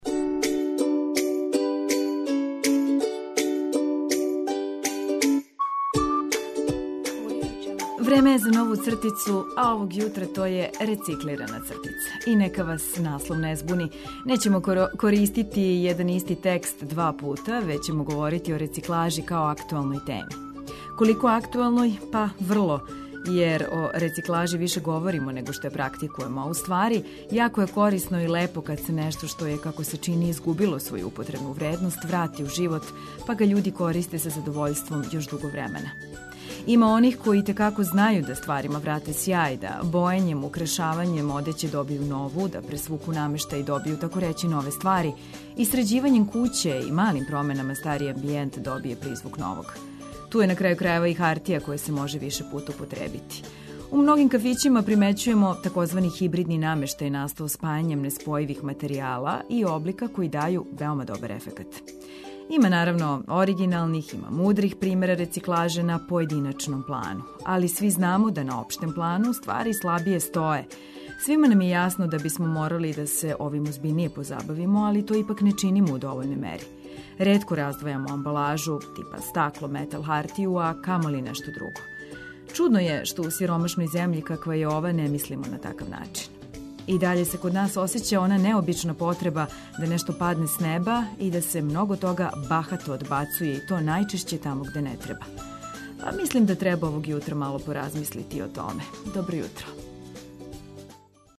Започните нови радни дан уз наш јутарњи програм прожет полетном музиком и важним информацијама.